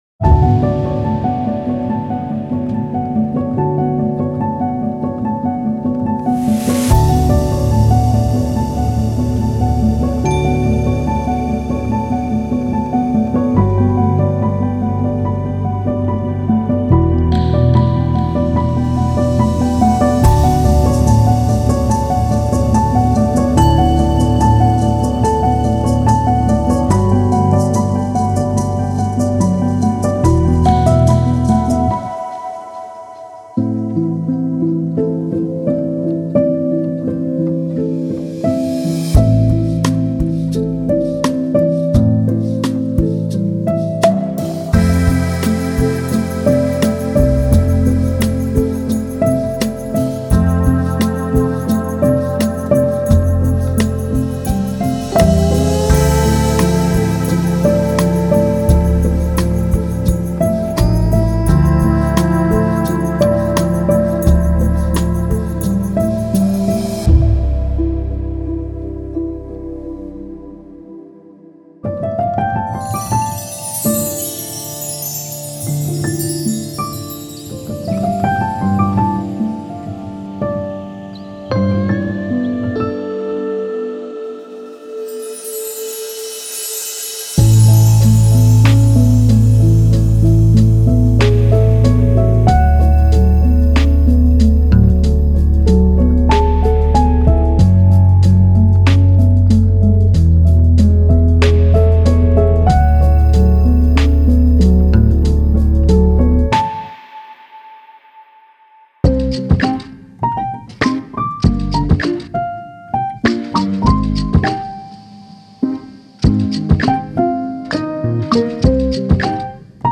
Genre:Filmscore
楽器のトーンを柔らげるために様々な素材が使用され、温かみのある表現力豊かなサウンドが生まれました。
各ループには、容易にレイヤーできるようコードパターンとそれに対応するメロディが含まれています。
プロフェッショナルなリボンマイクとハイエンドなスタジオ機材を使用し、演奏は極めて純度の高いクオリティで収録されています。